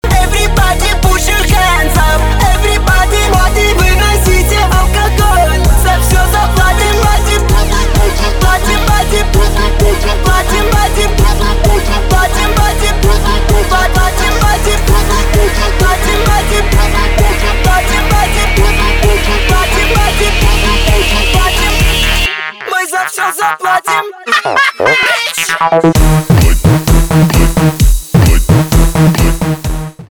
русский рэп
битовые , басы , качающие